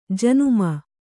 ♪ januma